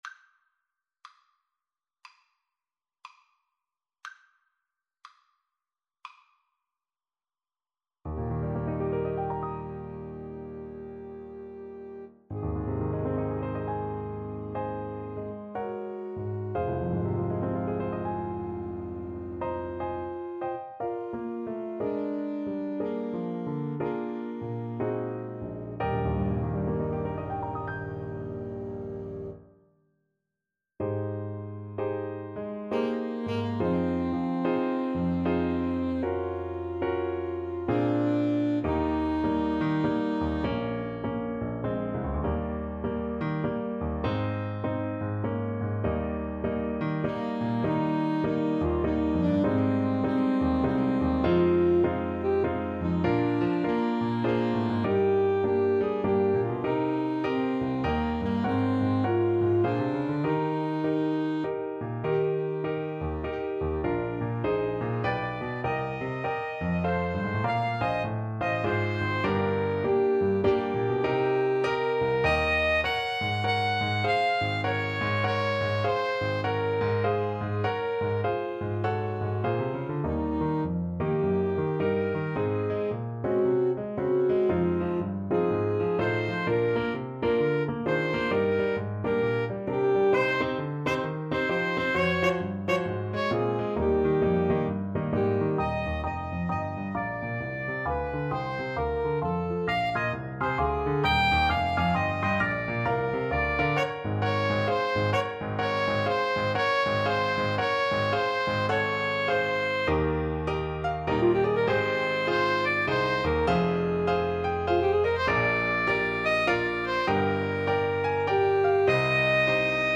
Alto SaxophoneTenor Saxophone
Adagio (swung throughout) =c.60
Jazz (View more Jazz Alto-Tenor-Sax Duet Music)